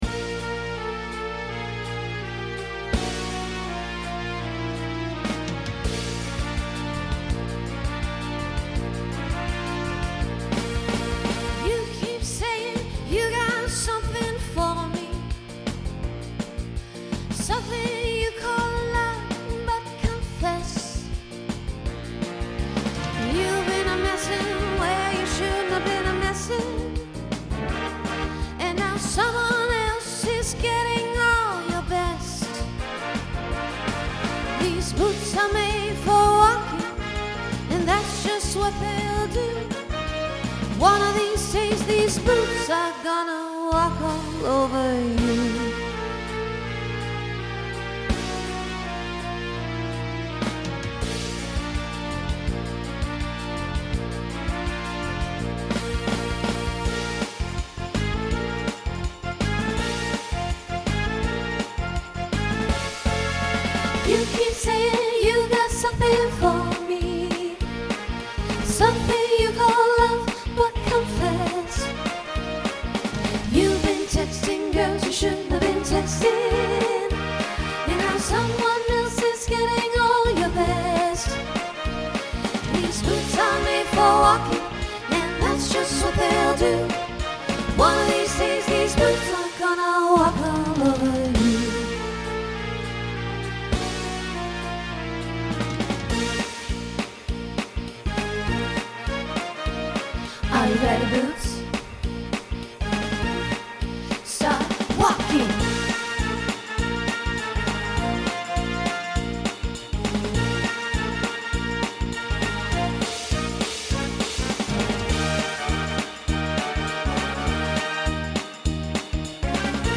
New SATB voicing for 2023.